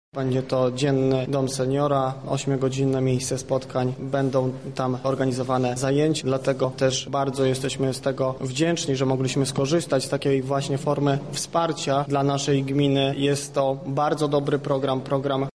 -Te programy to szansa rozwoju dla samorządu – mówi Andrzej Kozina, wójt gminy Turobin.